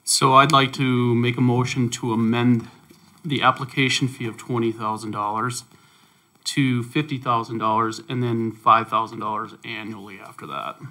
The Mobridge City Council held a special meeting Tuesday (August 31st) and approved ordinances on “the definition of cannabis dispensaries”, “restrictive zoning”, and “licensing provisions”.  Regarding the ordinance on licensing provisions, Councilman Brent Kemnitz made a motion to amend the licensing application fee.